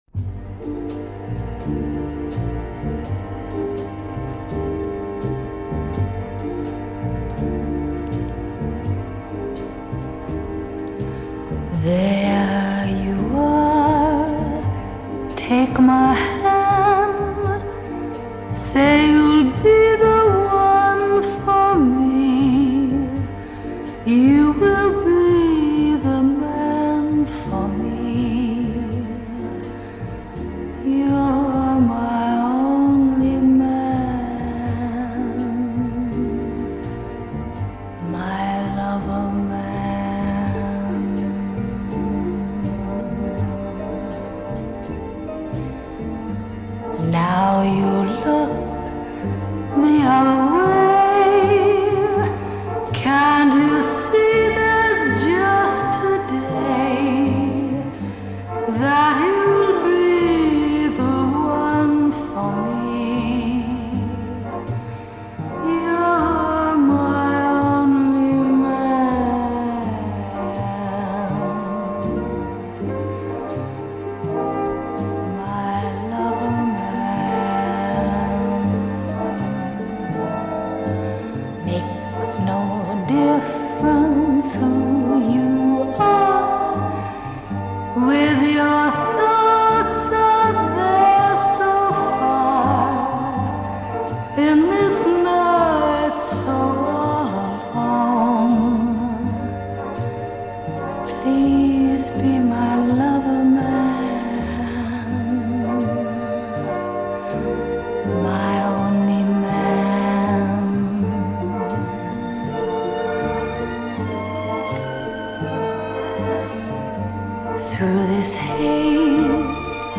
Tag: Jazz
Smooth and sultry